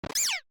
Cri de Poussacha dans Pokémon Écarlate et Violet.